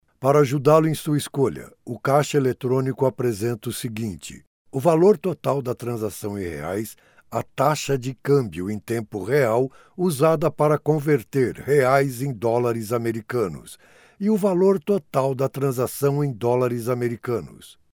locutora, latin american spanish voice over